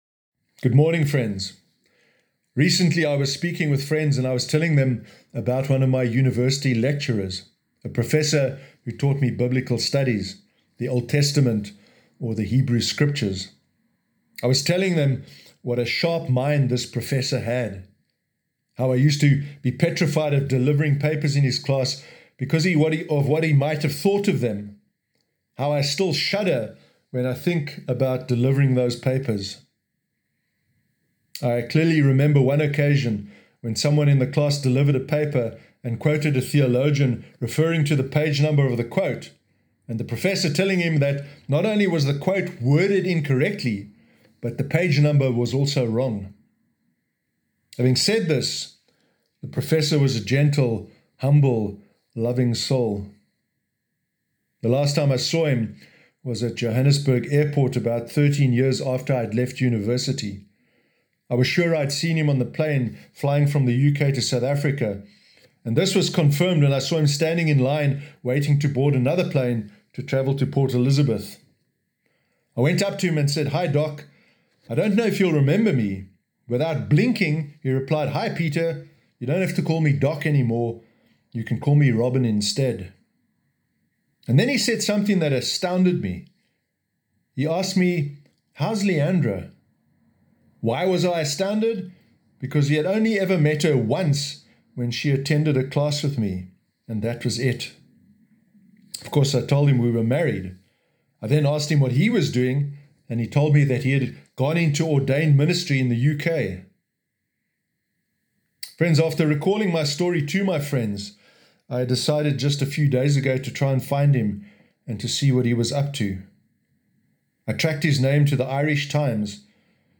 Sermon Sunday 30 August 2020